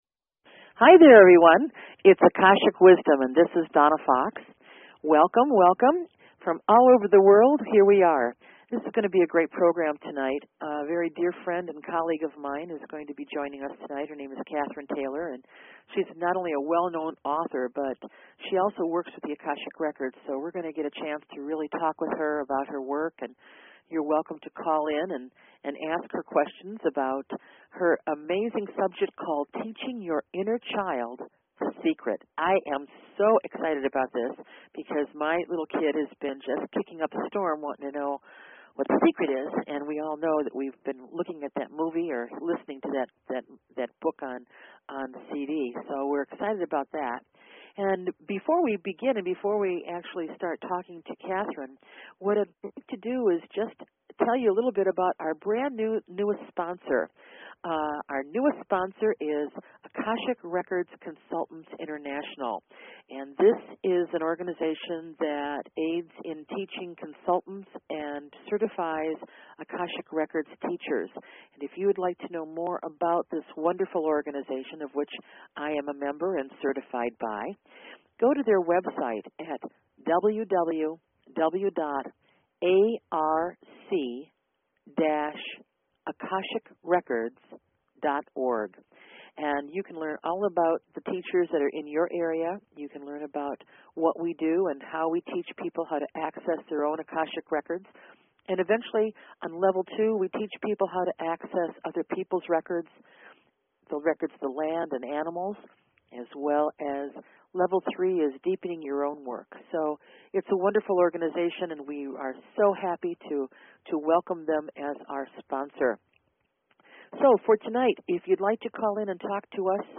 Debut of Akashic Wisdom talk show, March 4, 2007